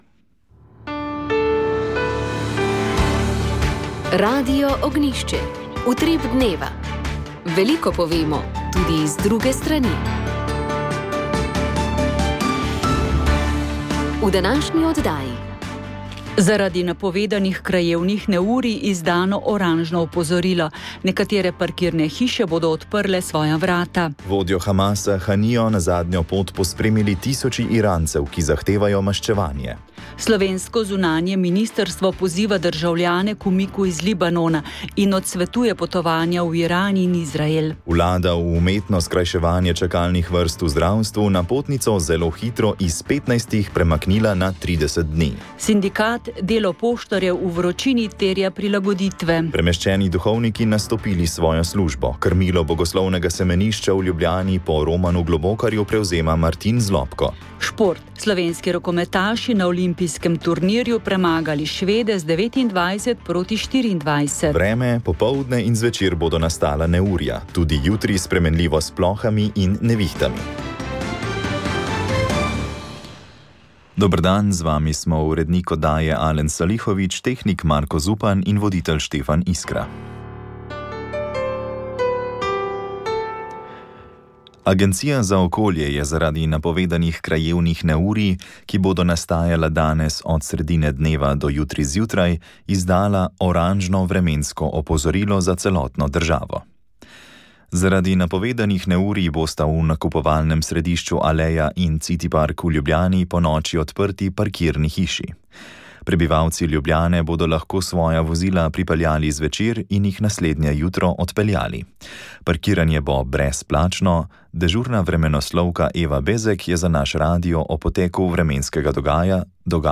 V pogovoru je pojasnil razloge za nekatere spremembe.